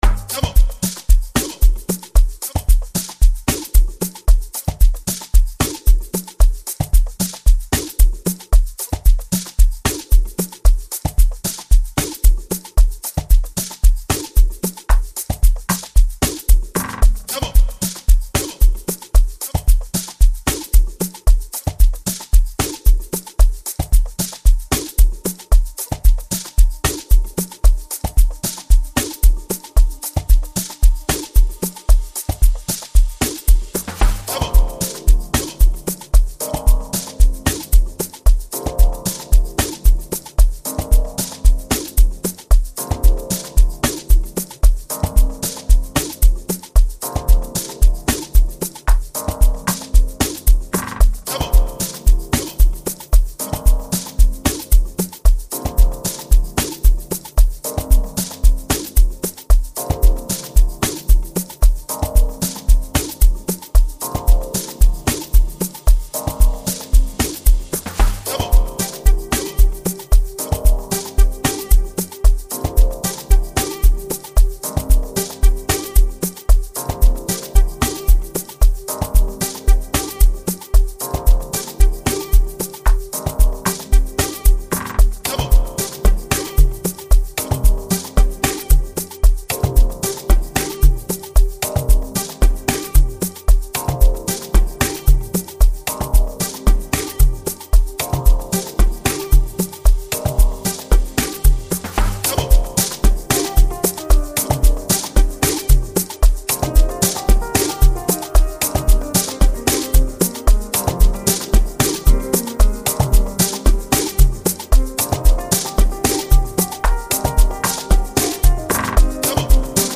soulful sounds